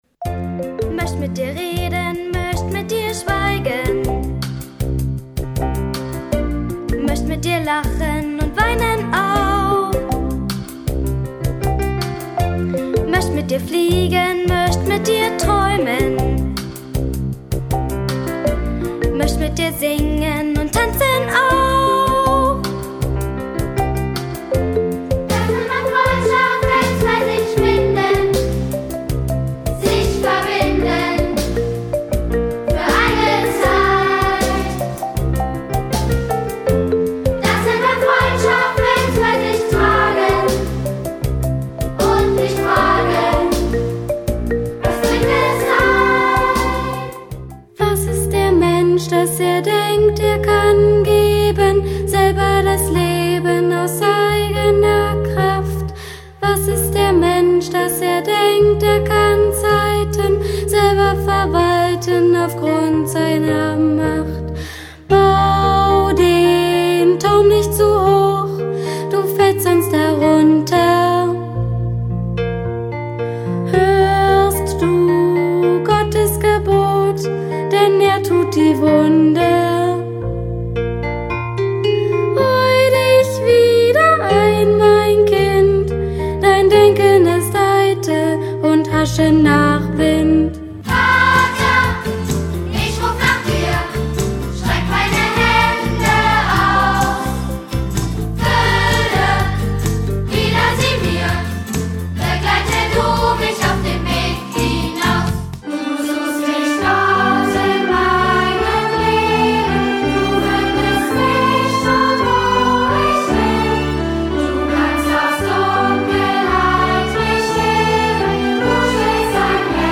Gemeindelied